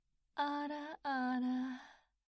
Ara Ara Mature Anime Woman
Etiquetas: meme, soundboard
ara-ara-mature-anime-woman.mp3